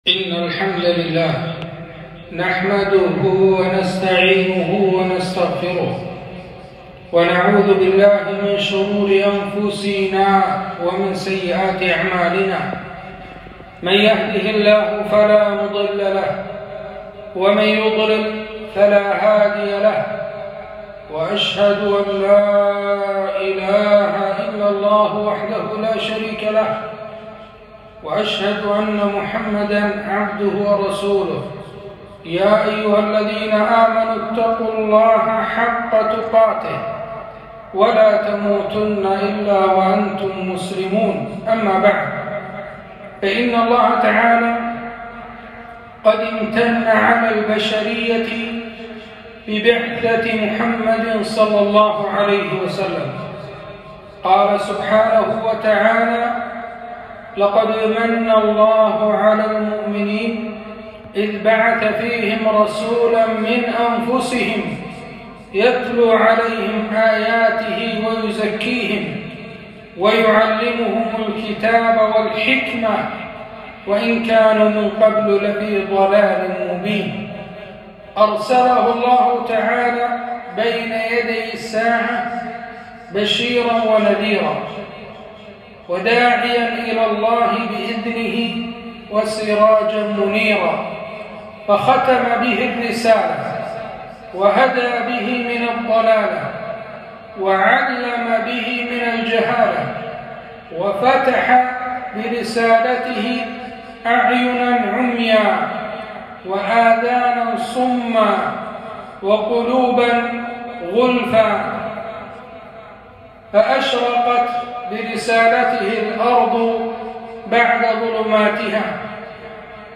خطبة - حقوق الرسول ﷺ علينا